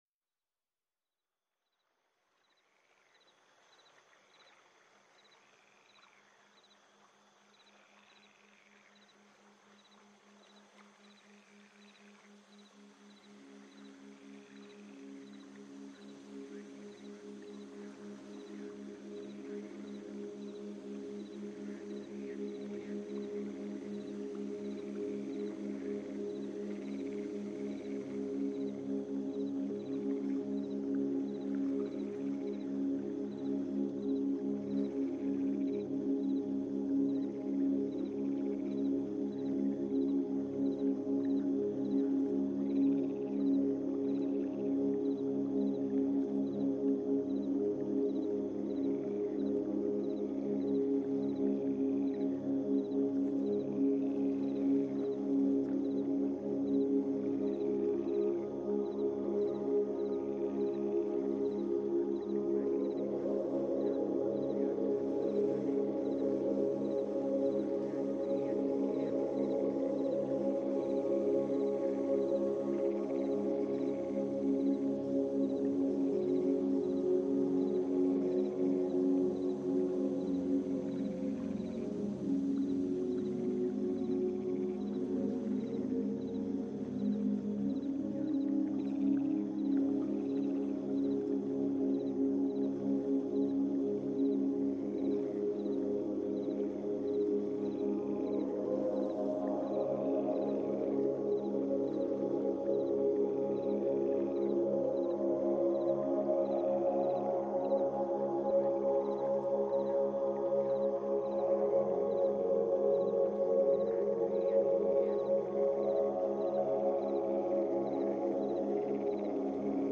Техника: Тета-бинауральные ритмы (частота 4 Гц).